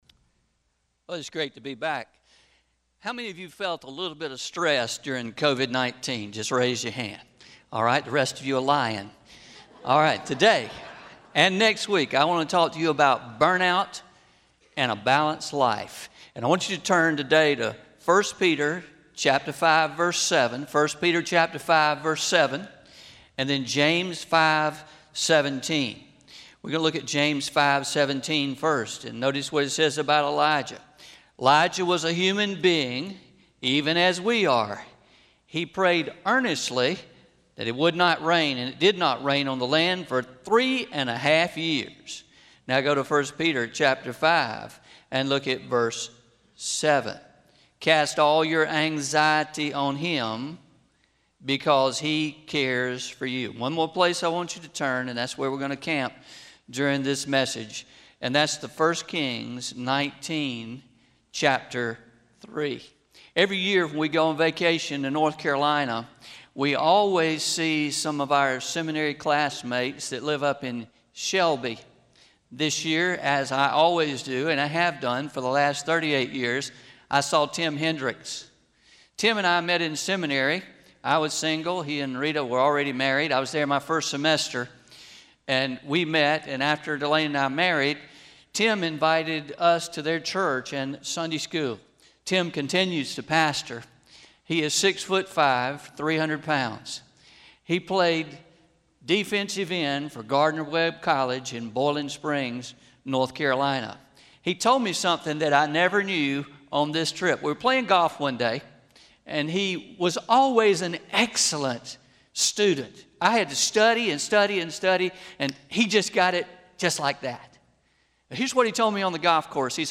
09-06-20am – Sermon – Burnout and a Balanced Life – Traditional